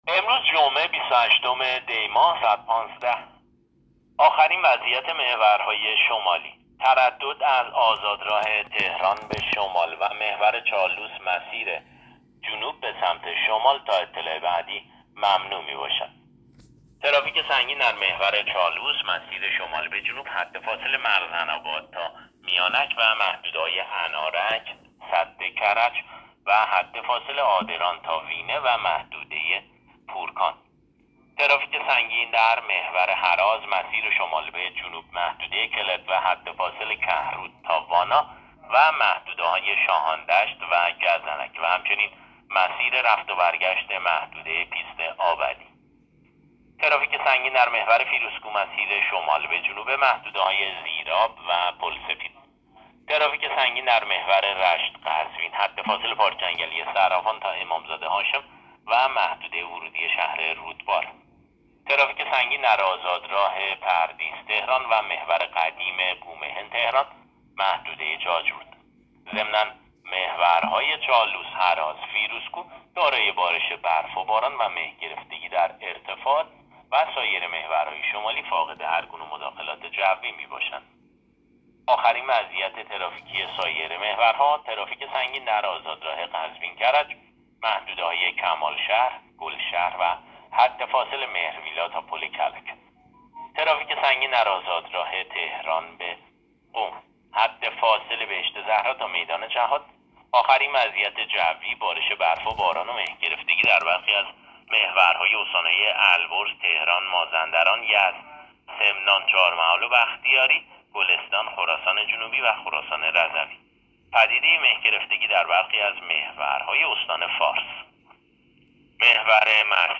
گزارش رادیو اینترنتی از آخرین وضعیت ترافیکی جاده‌ها تا ساعت ۱۵ بیست و هشتم دی؛